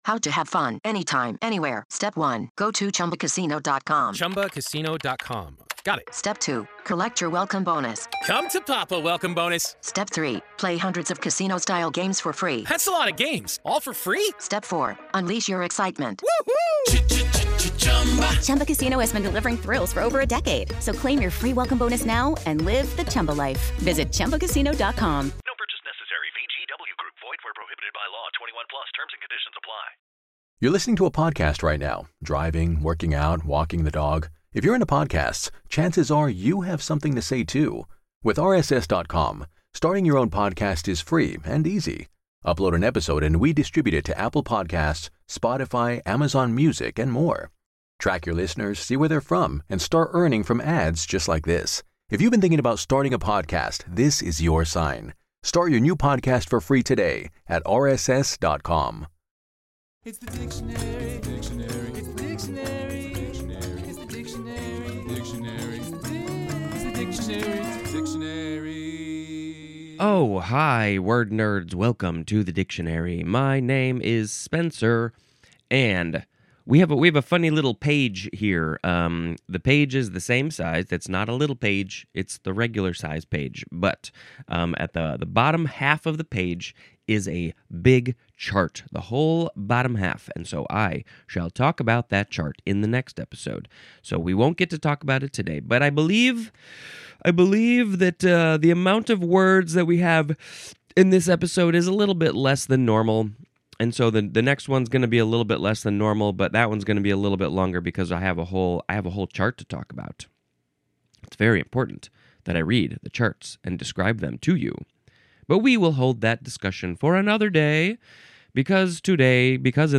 I read the English Dictionary in short sections, comment on it, make bad jokes, and sometimes sing little songs.